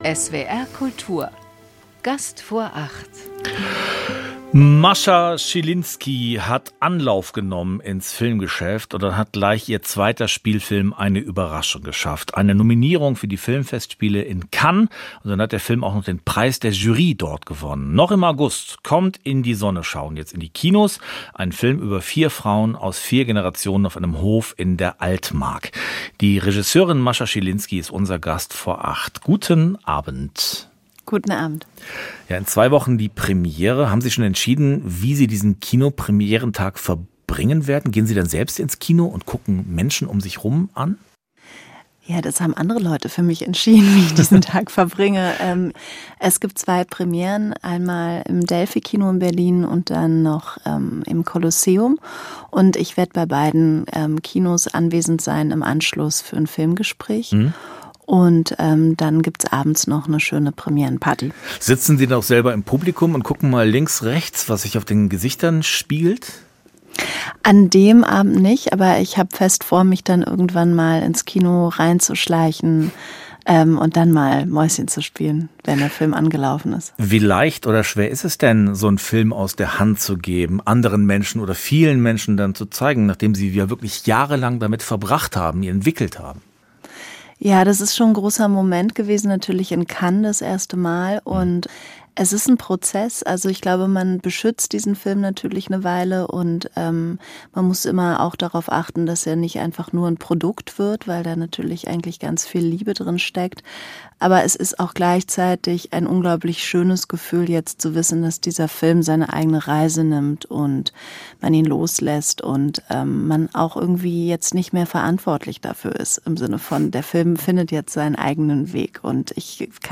„Surreal“ sei ihr und dem Filmteam dieser Erfolg erschienen, sagt Regisseurin Mascha Schilinski im Gespräch mit SWR Kultur.
mascha-schilinski-interview-wuensche-mir-filme-die-nach-anderen-erzaehlweisen-suchen.mp3